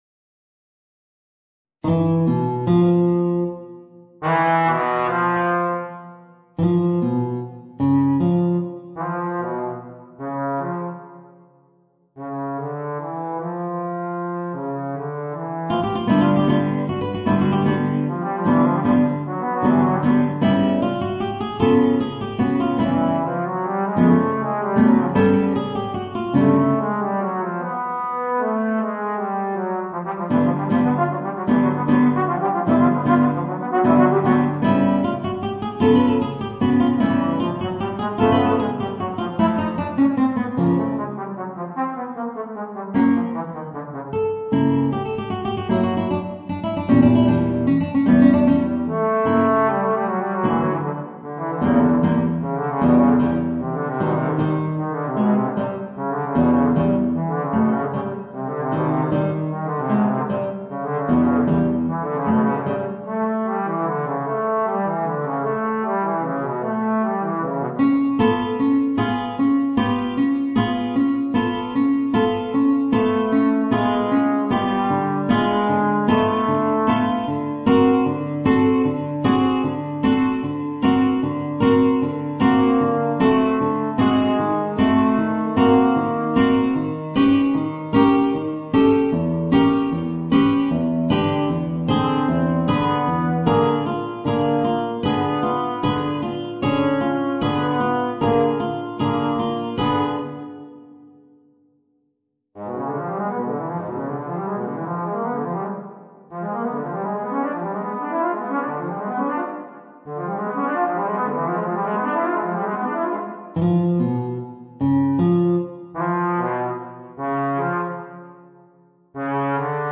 Voicing: Trombone and Guitar